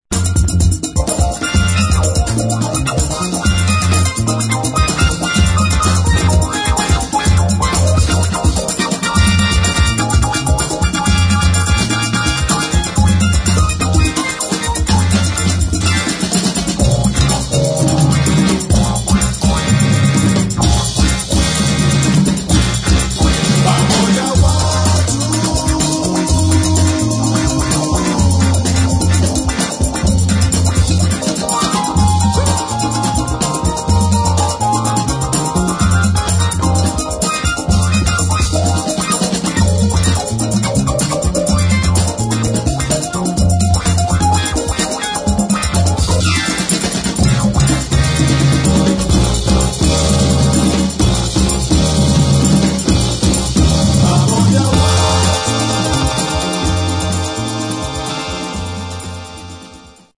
[ FUNK / LATIN ]